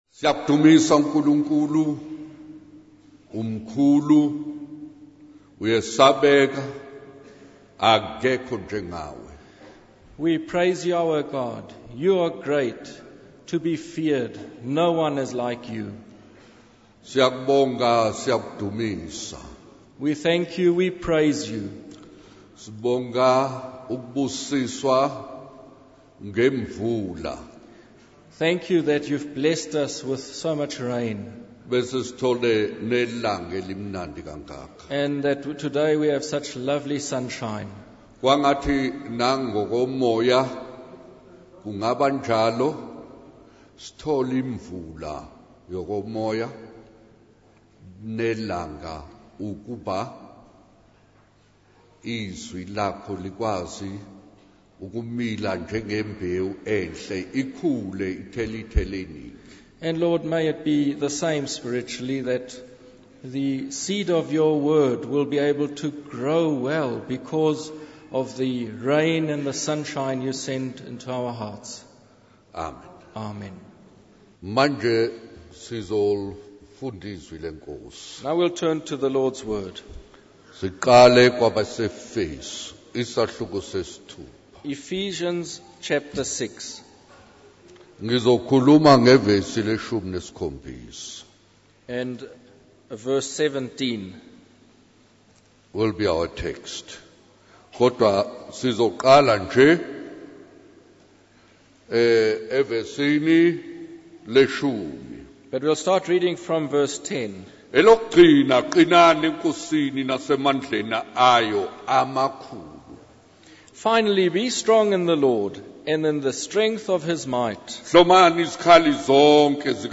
In this sermon, the speaker shares a personal story about a young girl who had to make a decision between keeping a valuable ring or using the money to spread the message of God.